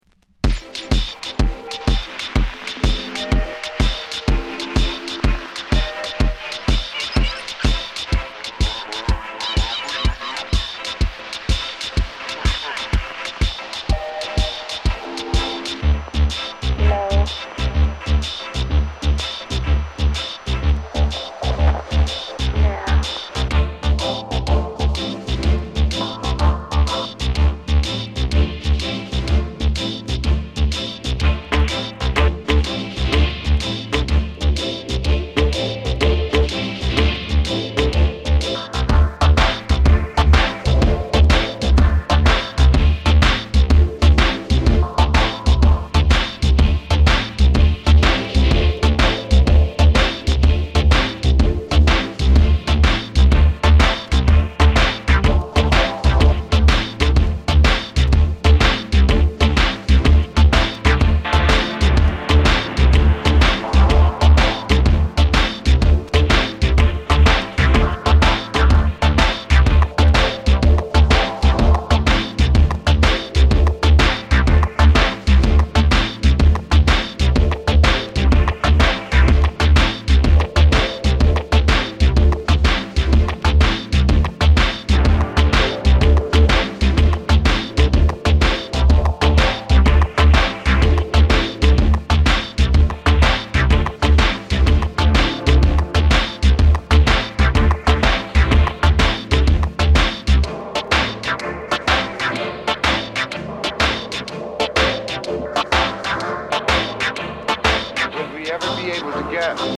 浮遊感のあるシンセを硬質ビートで撃ち抜いた